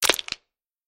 На этой странице собраны звуки перелома костей – от резких щелчков до глухих тресков.
Ломающиеся ребра звук